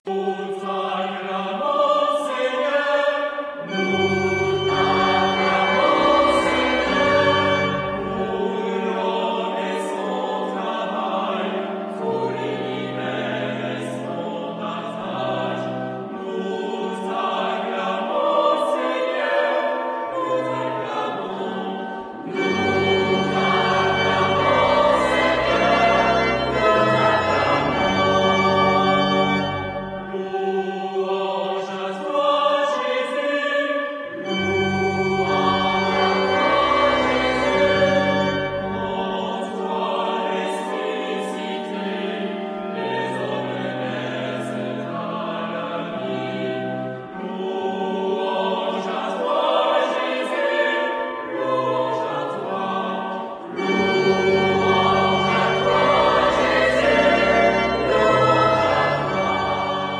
Genre-Style-Forme : Hymne (sacré) ; Sacré
Caractère de la pièce : festif ; joyeux
Type de choeur : SATB  (4 voix mixtes )
Instrumentation : Orgue
Tonalité : sol mineur